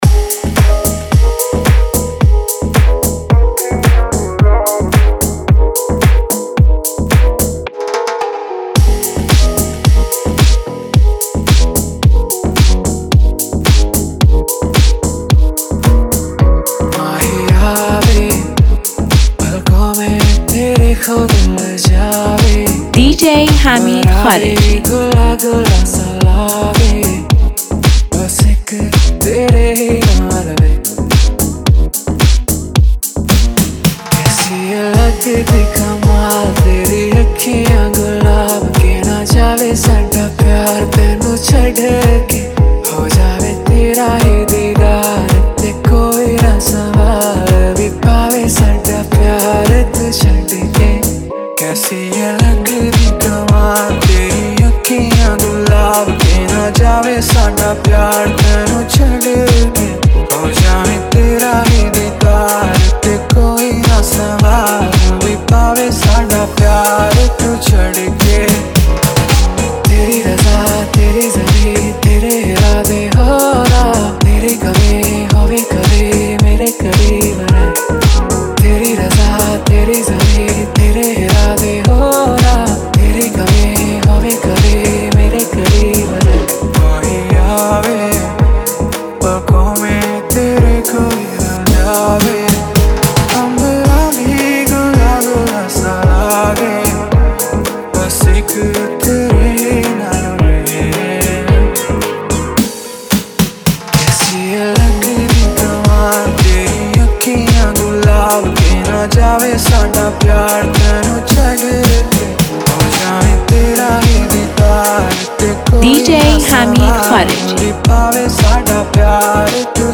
ریمکس هندی